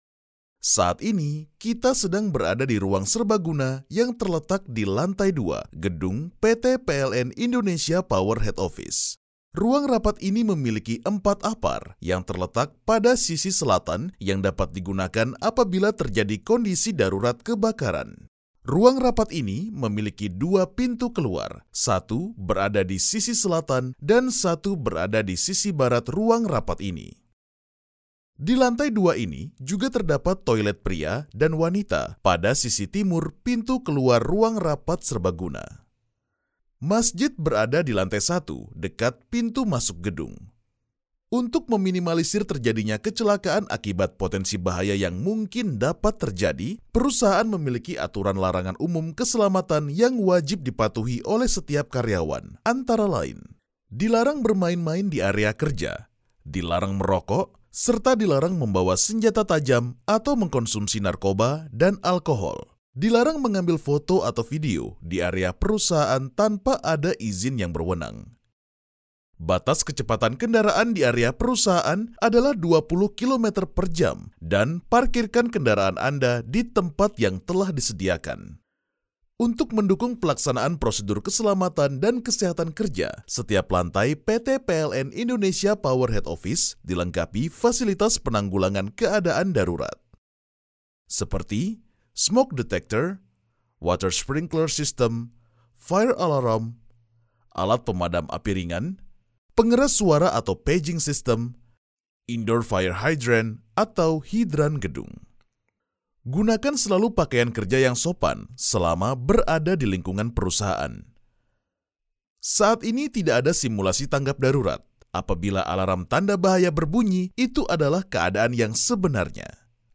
Commerciale, Cool, Polyvalente, Mature, Chaude
Guide audio